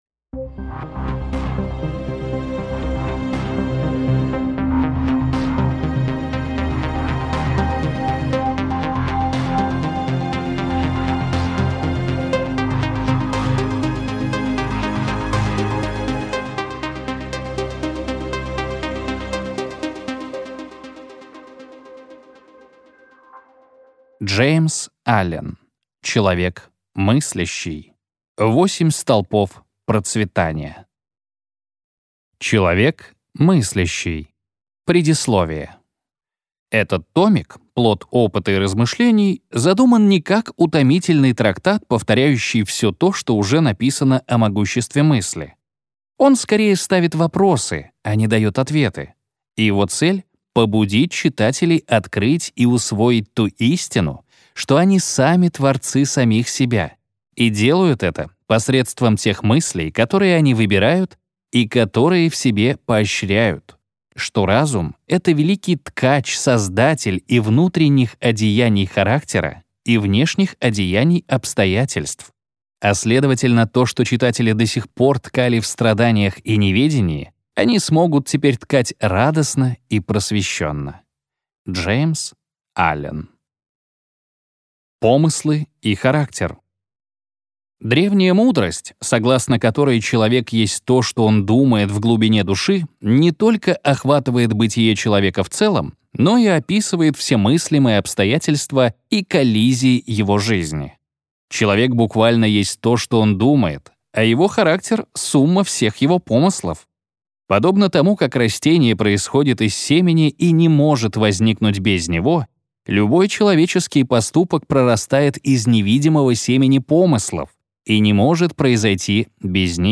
Аудиокнига Человек мыслящий. 8 столпов процветания | Библиотека аудиокниг